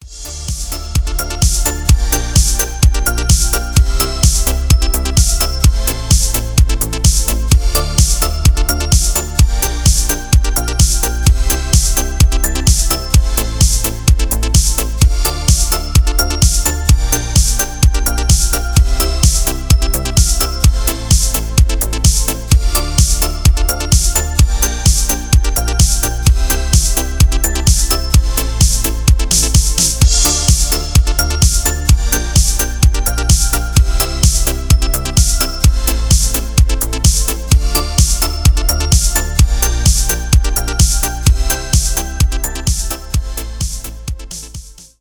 танцевальные , synth pop
без слов , итало диско